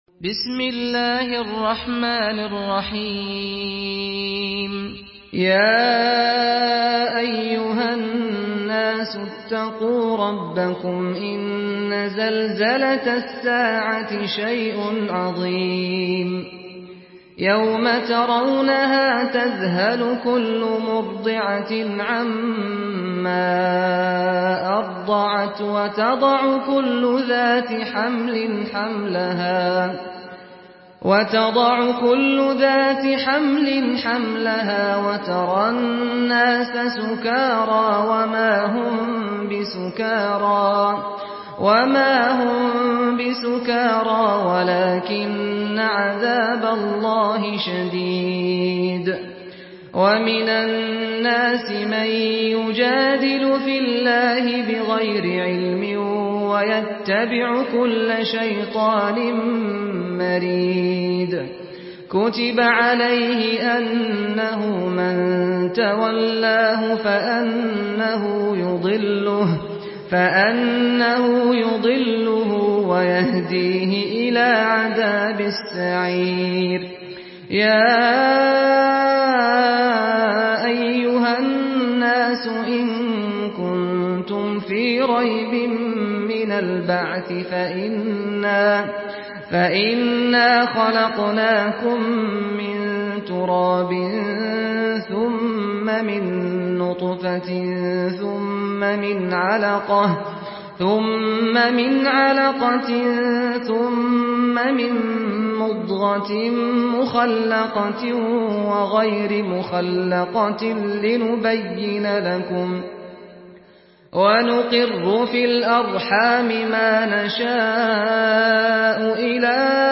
Surah Al-Haj MP3 in the Voice of Saad Al-Ghamdi in Hafs Narration
Murattal Hafs An Asim